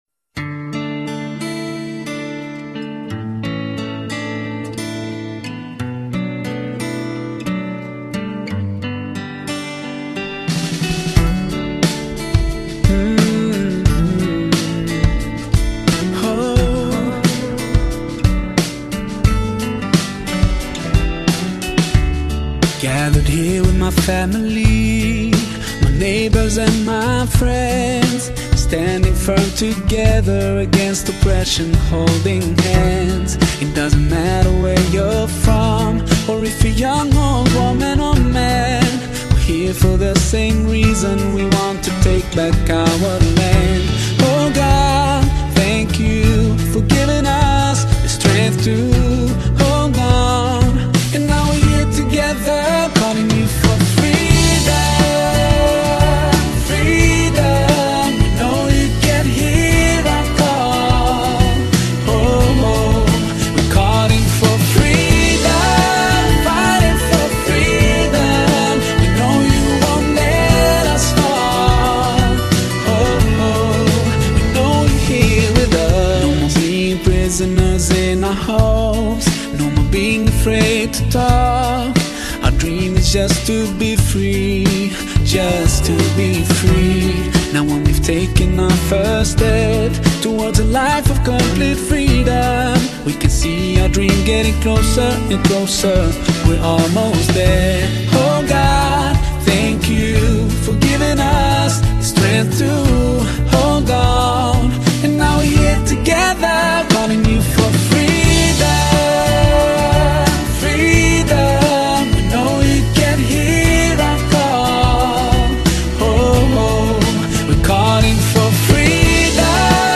Arabic Islamic Song